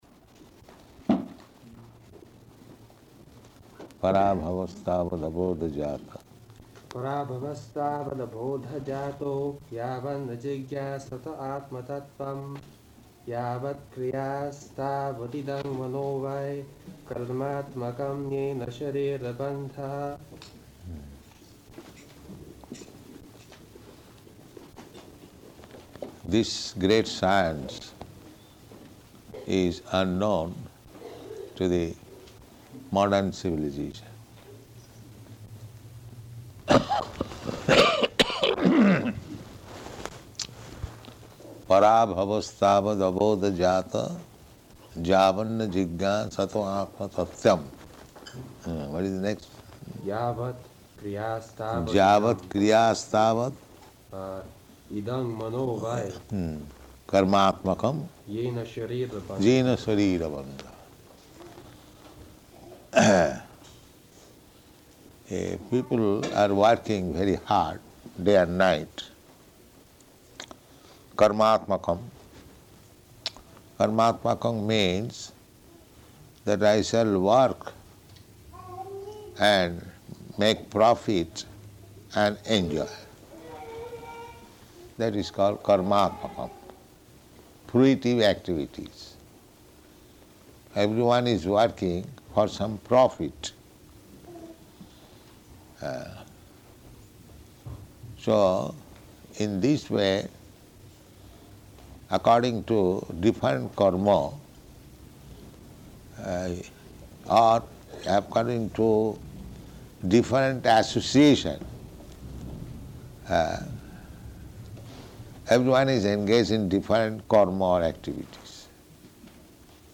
Śrīmad-Bhāgavatam 5.5.5 --:-- --:-- Type: Srimad-Bhagavatam Dated: September 3rd 1971 Location: London Audio file: 710903SB-LONDON.mp3 Prabhupāda: Parābhavas tāvad abodha-jātaḥ.